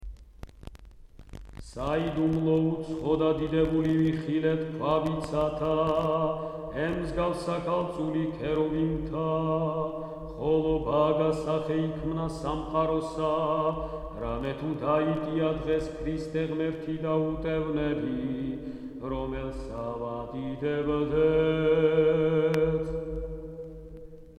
Christmas carols
Keywords: ქართული ხალხური სიმღერა